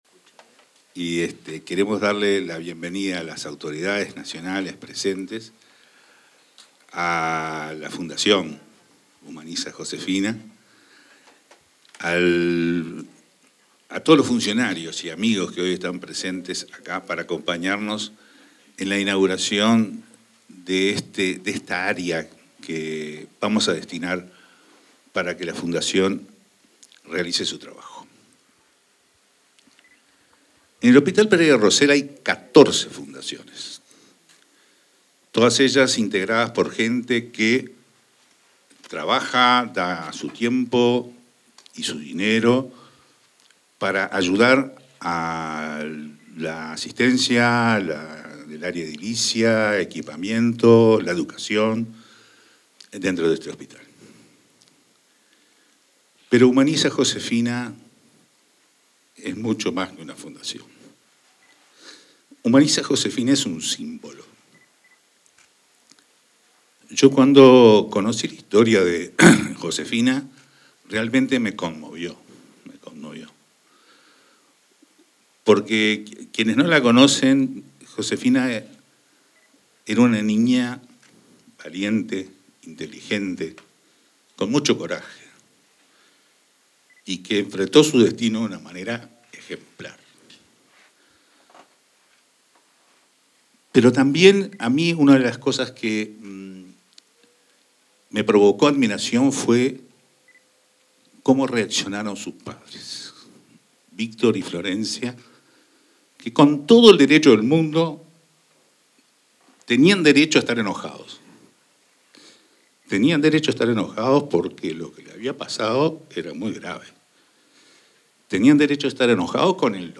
Inauguración de aula para niños internados en hospital Pereira Rossell